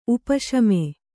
♪ upa śame